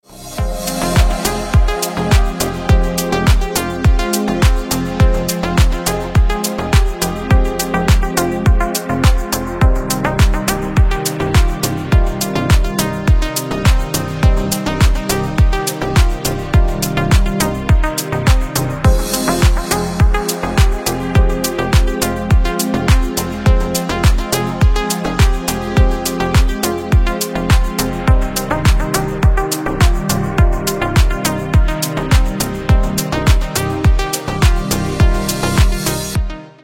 Красивые мелодии на телефон, мелодичные рингтоны Качество